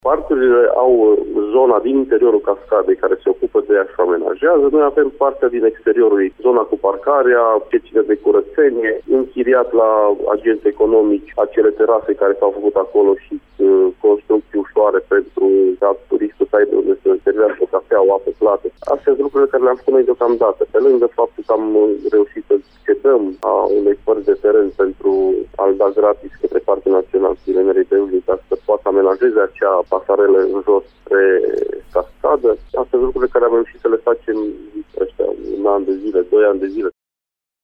După aproape 20 de ani în care nicio autoritate de profil nu a intervenit aproape deloc în ceea ce priveşte amenajarea perimetrului care, în parte este administrat de Primăria Bozovici şi parţial de Administraţia Parcului Naţional Cheile Nerei-Beuşniţa, Unitatea administrativ teritorială a comunei cărăşene a investit după cum s-a priceput mai bine în amenajarea zonei spune primarul Adrian Stoicu: